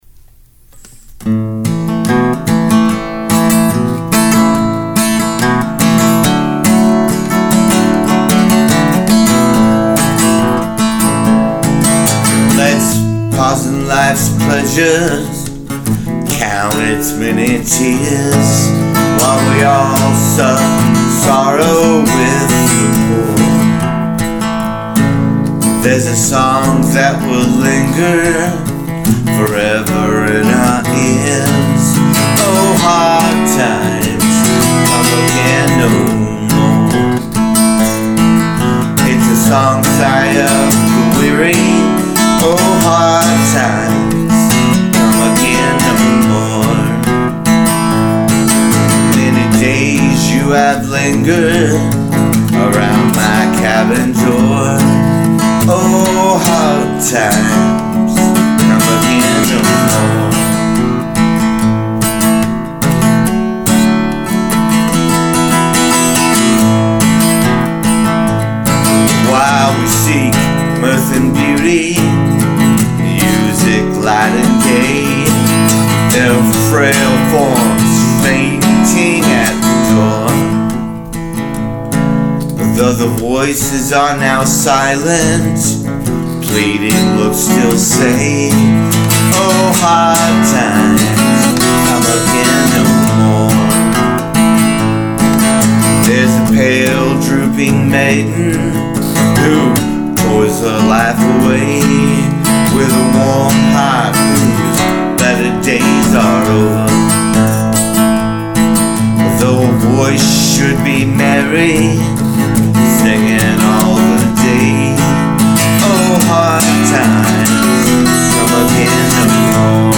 Hard Times, Come Again No More live
hard-times-come-again-no-more-live.mp3